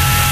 ciwsFiringLoop.ogg